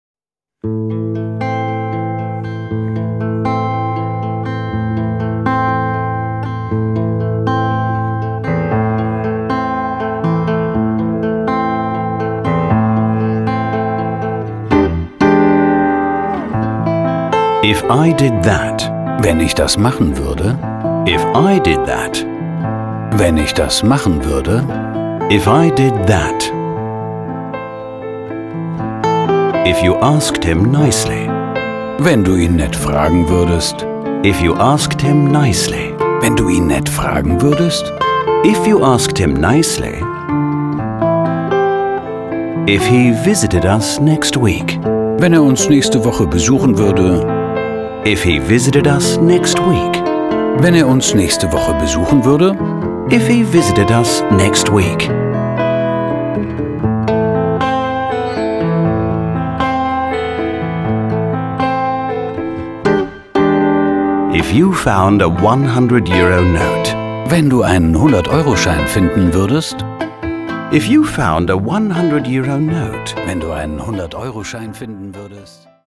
Small Talk - Classic Grooves.Classic Grooves mit Motiven von Bach, Chopin & Händel / Audio-CD mit Booklet